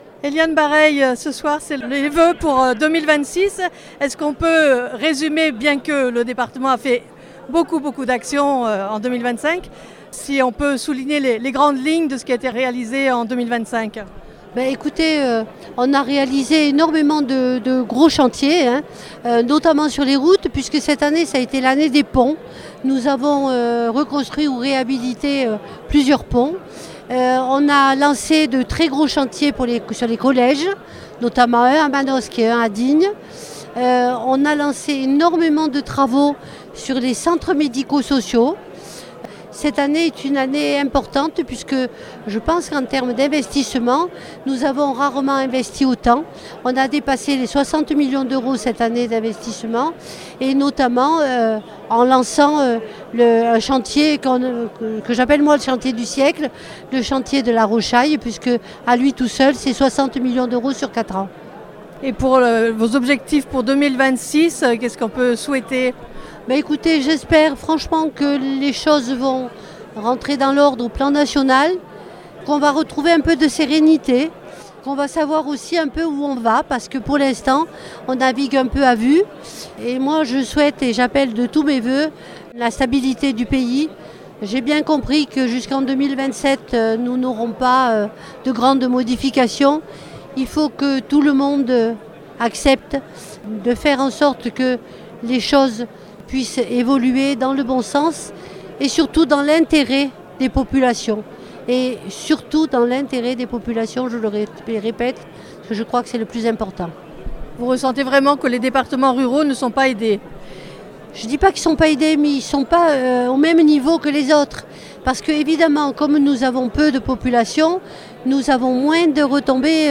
L'interview d' Eliane Barreille Présidente du Conseil Départemental des Alpes de Haute Provence.